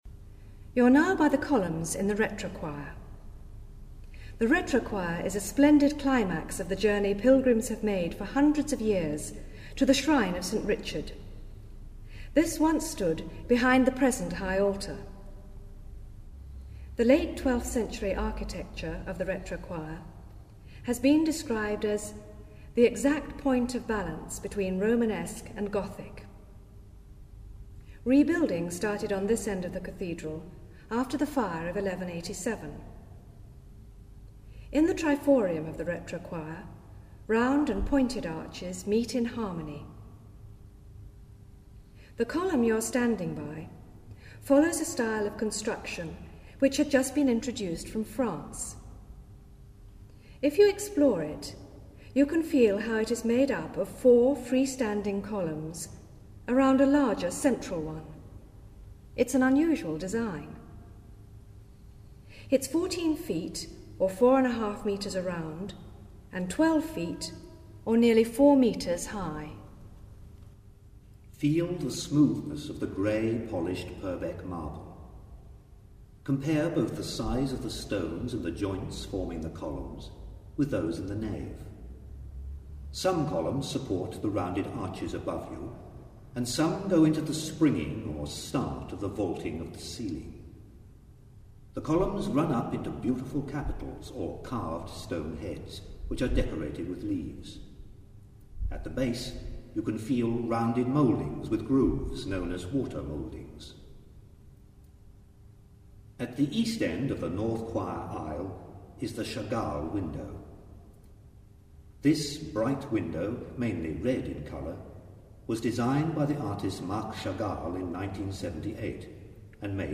An Acoustic Fingerprint Guide of Chichester Cathedral - 5: Retrochoir and Lady Chapel